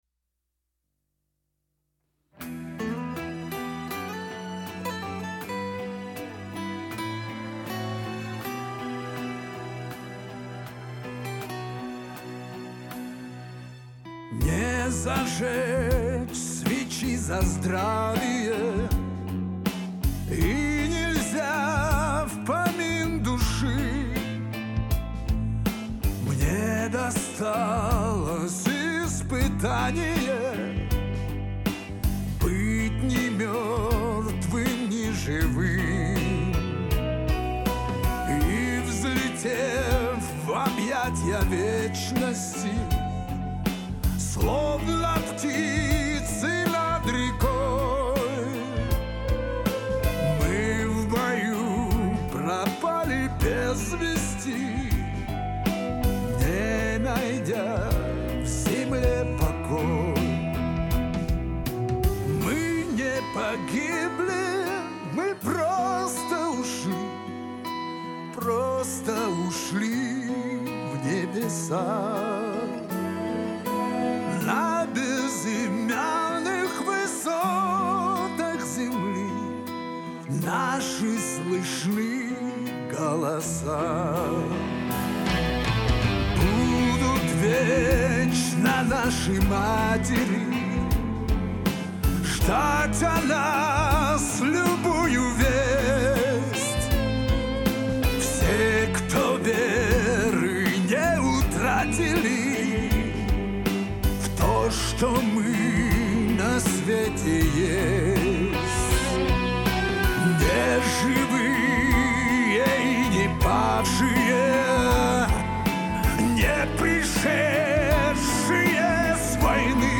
минусовка версия 224309